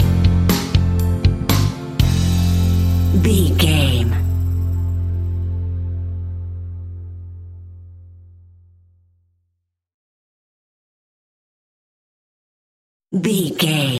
Ionian/Major
pop rock
indie pop
fun
energetic
uplifting
upbeat
groovy
guitars
bass
drums
organ